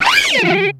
Cri de Dimoret dans Pokémon X et Y.